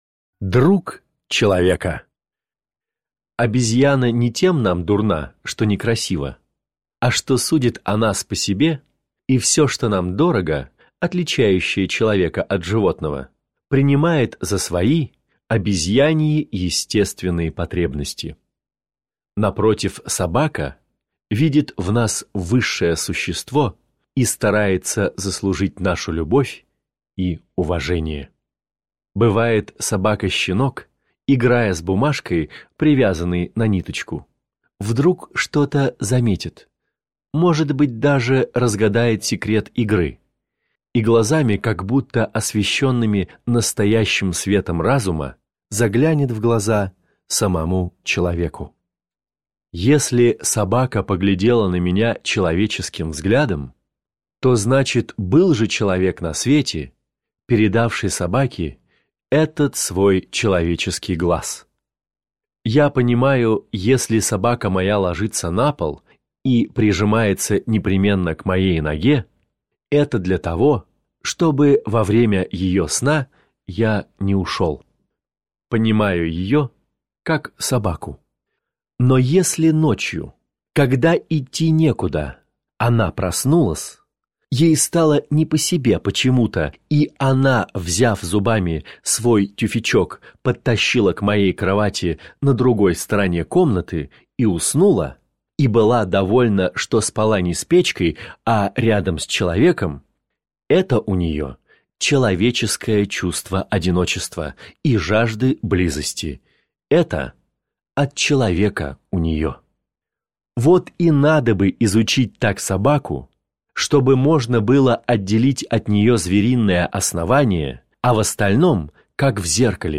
Аудиокнига в разделах